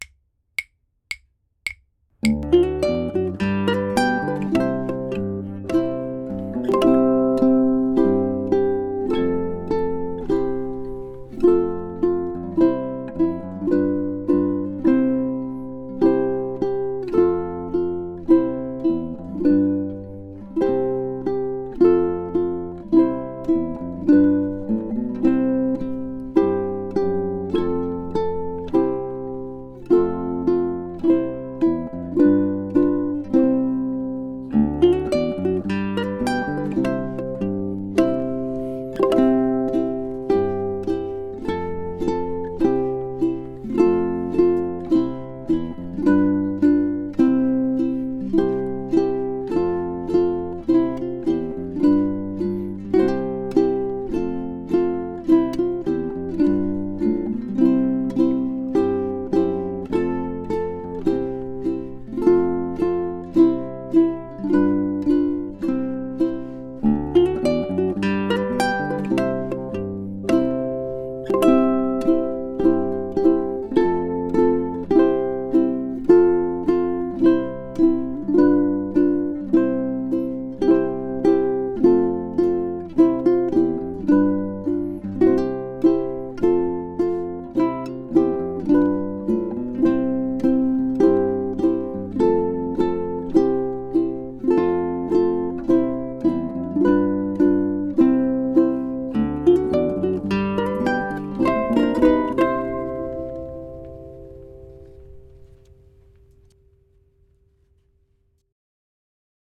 ʻImo ʻImo features a Hawaiian style vamp, i.e., a short introduction: the first two measures—the vamp—are taken up with only D7, G7 and C chords.
ʻukulele
In the ʻImo ʻImo track, we use the Down 2X strum the first time through and switch to the Flat-Four strum on the repeat.